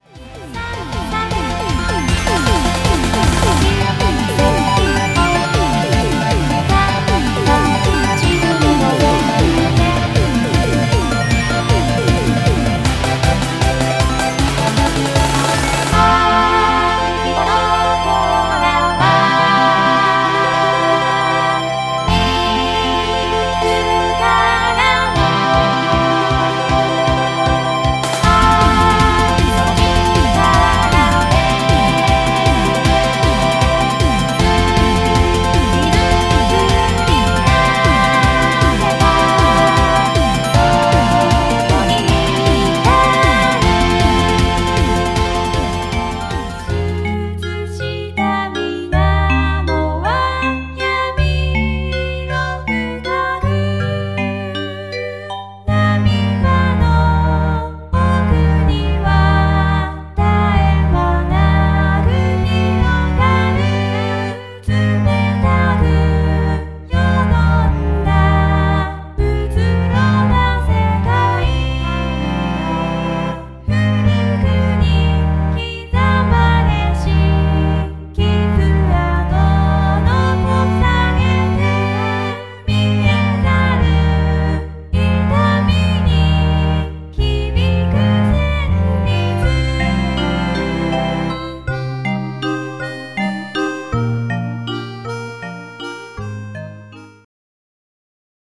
・オリジナル人造ボーカルアルバム